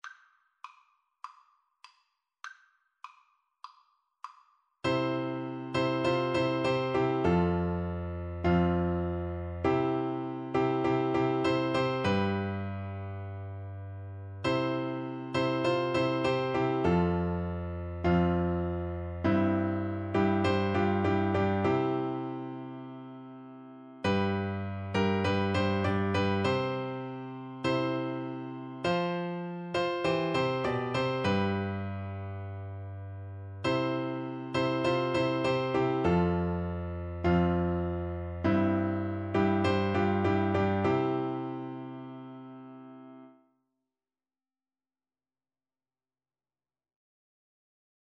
C major (Sounding Pitch) (View more C major Music for Oboe )
4/4 (View more 4/4 Music)
Classical (View more Classical Oboe Music)